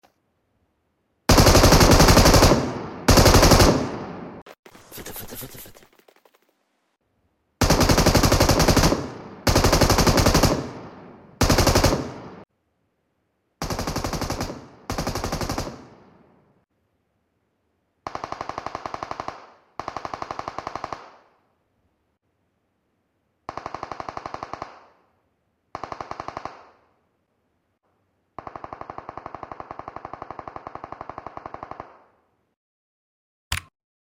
M249 Sound in PUBG MOBILE sound effects free download